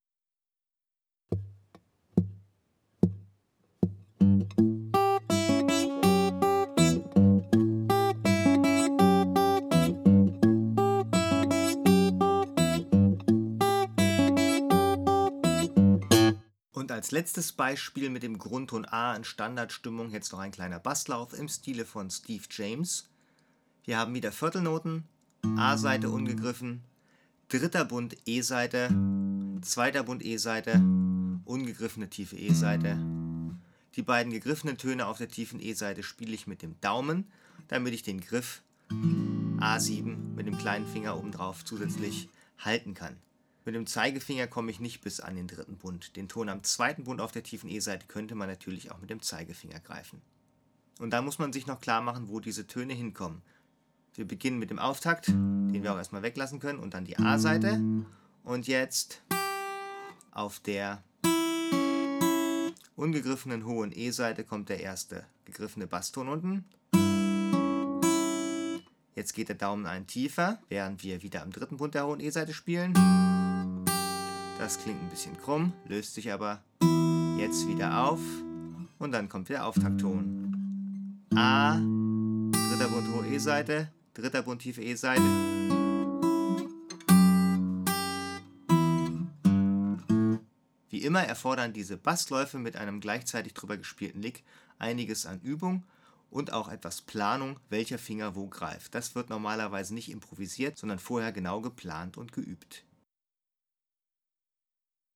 Lese- / Hörproben zu Garantiert Akustik Bluesgitarre Lernen
sound_clipping 184 – Standard-Tuning, A Bass-Riff
184-Standard-Tuning-A-Bass-Riff.mp3